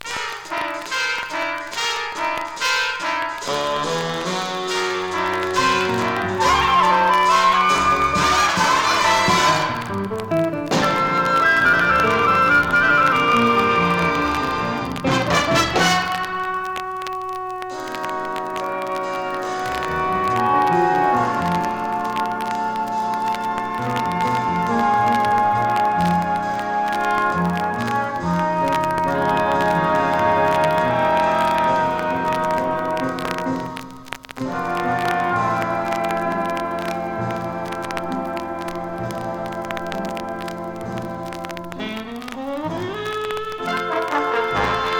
Jazz　USA　12inchレコード　33rpm　Mono
ジャケ擦れ汚れ背ダメージ　盤キズ多チリノイズ多